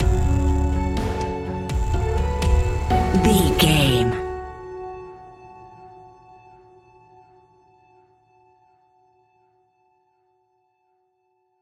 Ionian/Major
F♯
techno
trance
synths
synthwave